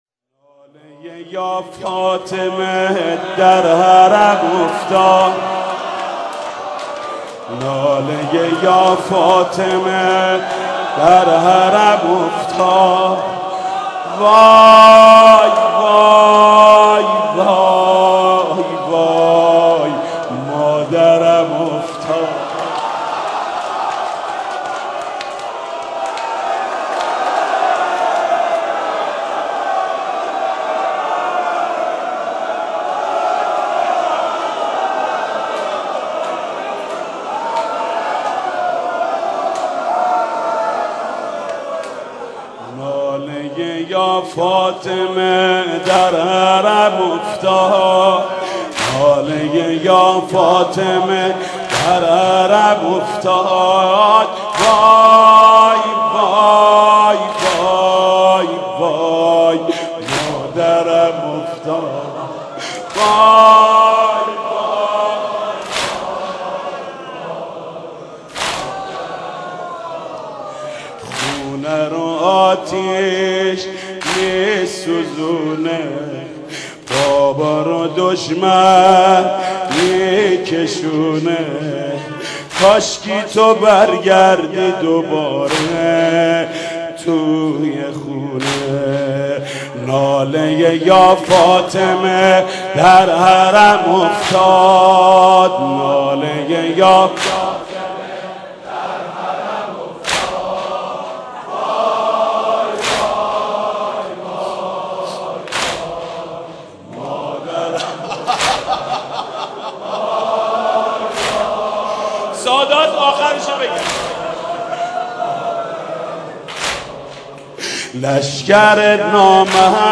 دانلود آهنگ های مداحی و نوحه